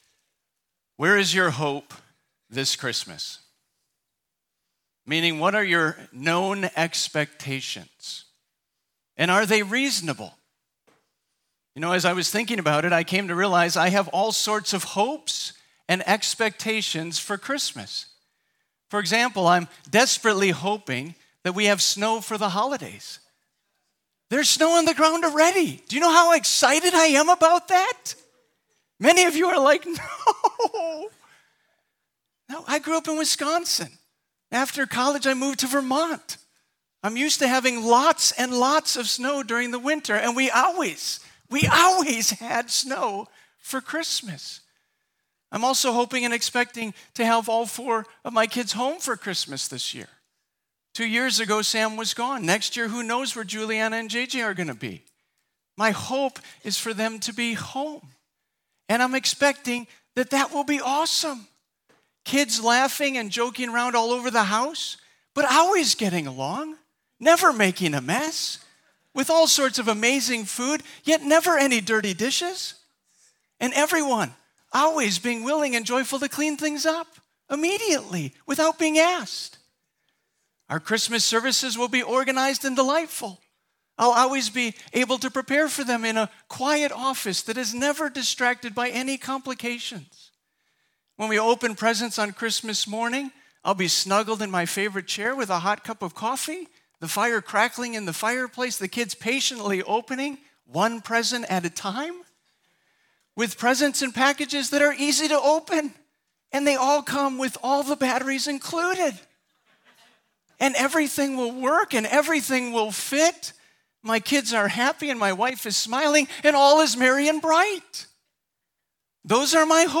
Rejoicing In God Sermons podcast